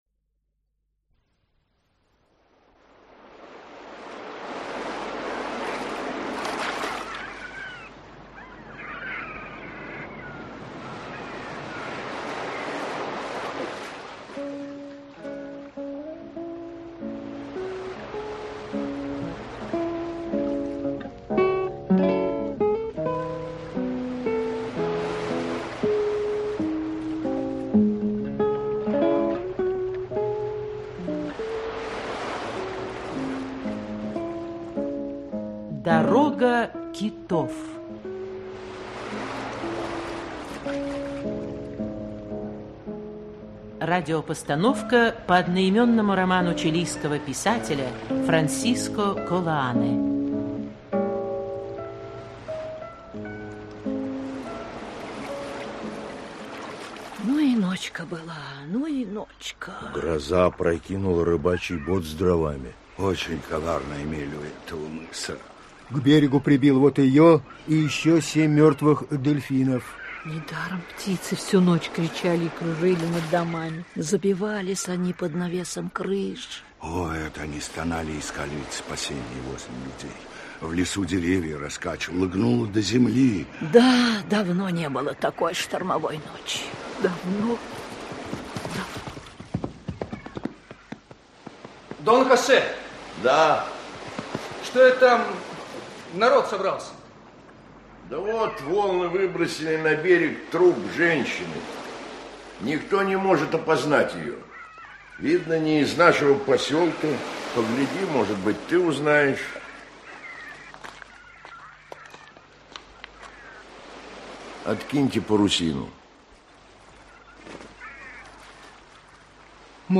Аудиокнига Дорога китов | Библиотека аудиокниг
Aудиокнига Дорога китов Автор Франсиско Колоане Читает аудиокнигу Актерский коллектив.